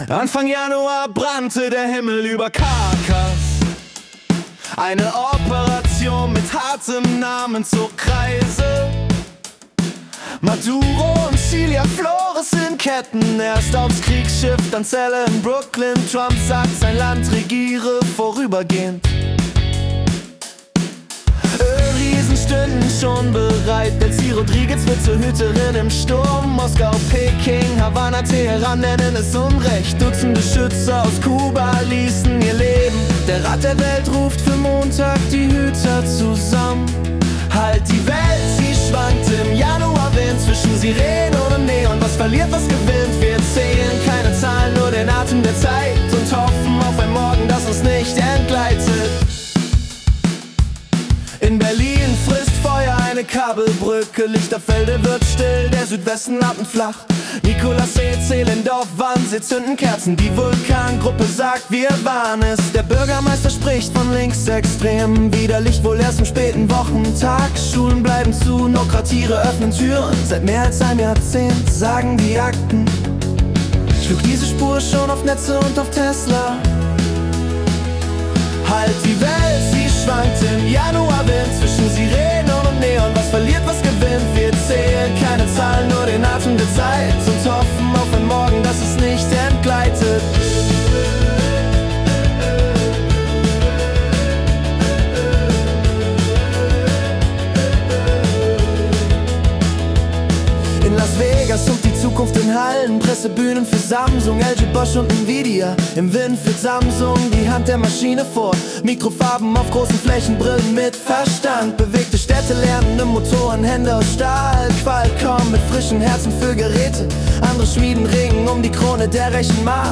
Januar 2026 als Singer-Songwriter-Song interpretiert.